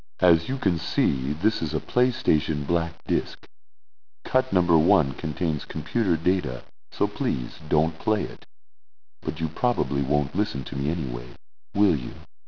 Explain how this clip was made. at the beginning of the secret song track